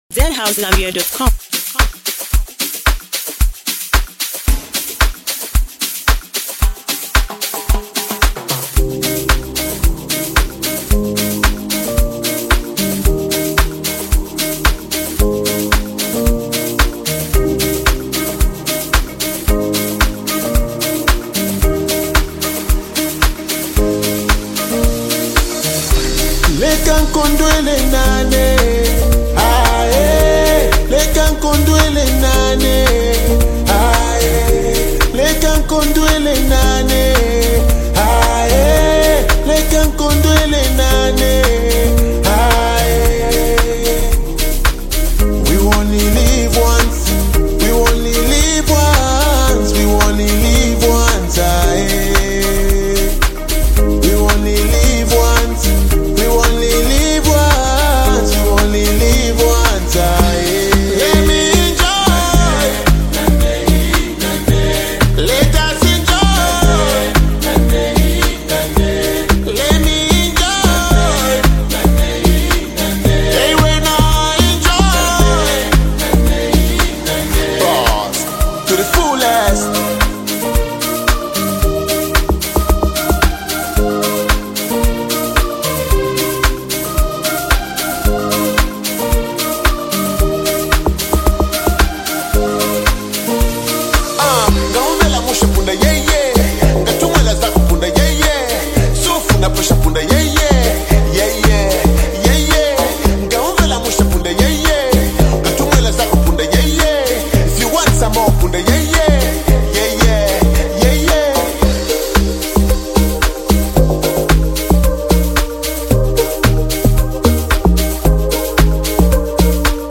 With catchy hooks and uplifting energy